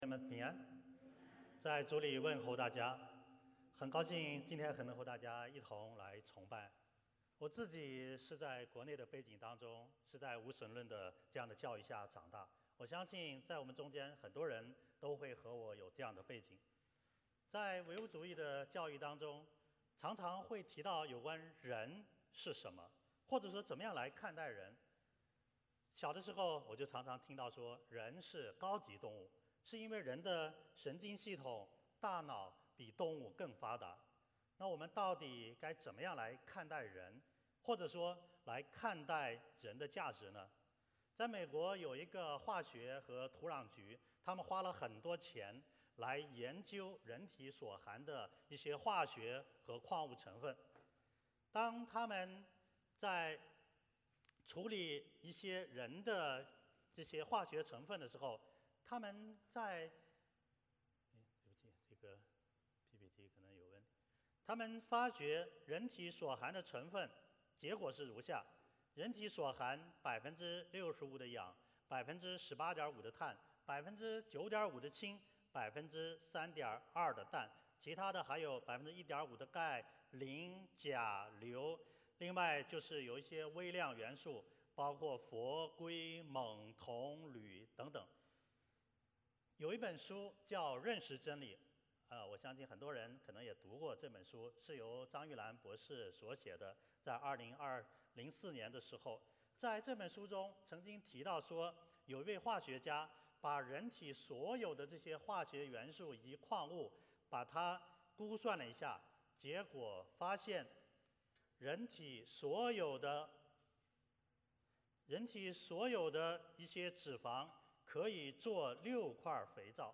Passage: 路加福音 8:26-39 Service Type: 主日崇拜 欢迎大家加入我们的敬拜。